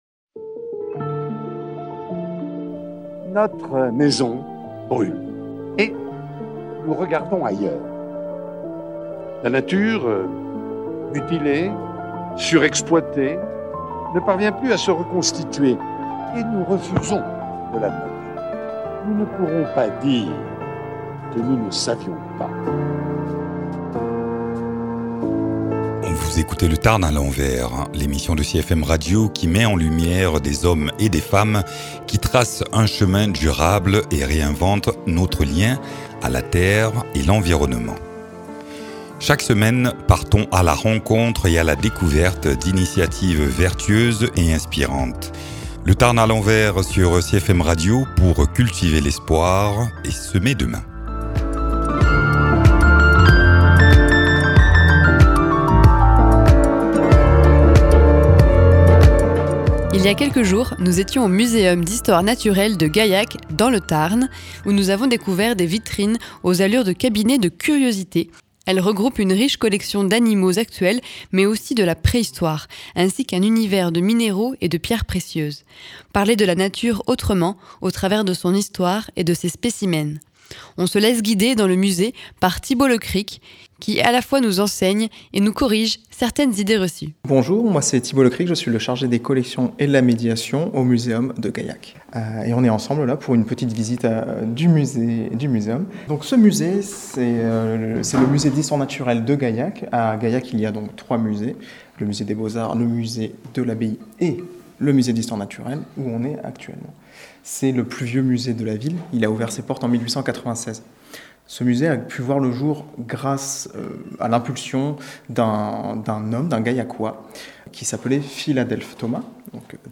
Visite guidée du Muséum d’Histoire Naturelle de Gaillac